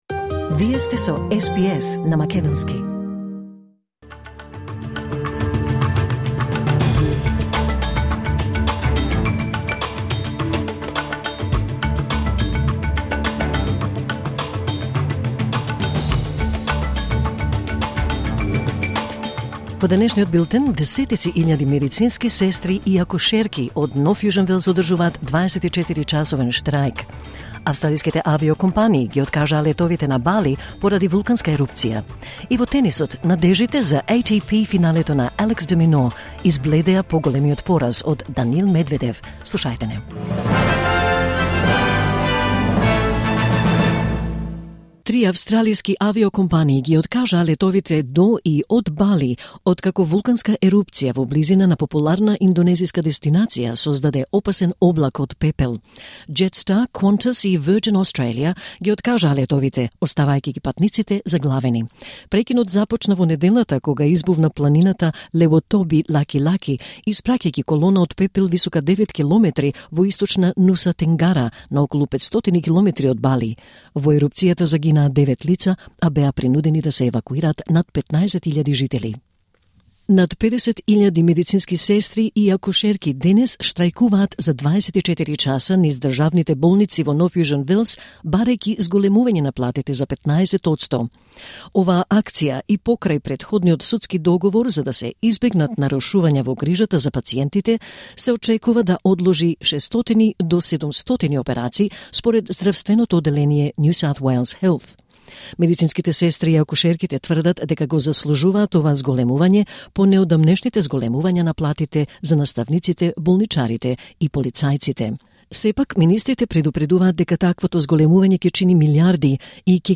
Вести на СБС на македонски 13 ноември 2024